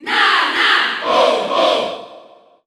File:Ice Climbers Cheer French SSBU.ogg
Category: Crowd cheers (SSBU) You cannot overwrite this file.
Ice_Climbers_Cheer_French_SSBU.ogg.mp3